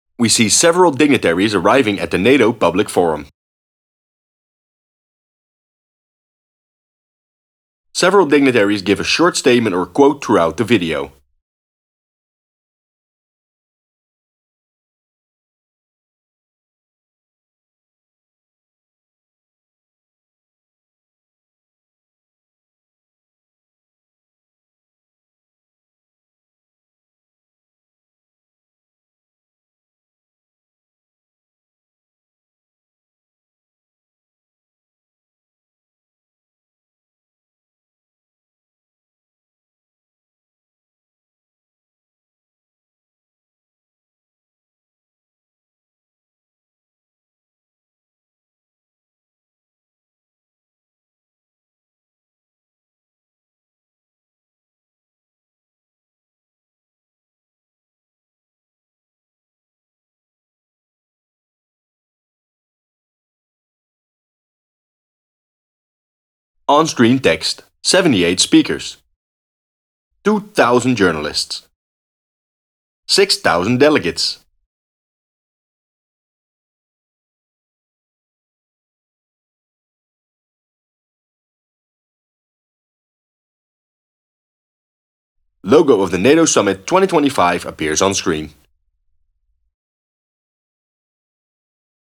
*Inspring music plays*
Several dignitaries give a short statement or quote throughout the video.
*Music crescendoes*